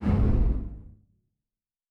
Fantasy Interface Sounds
Special Click 33.wav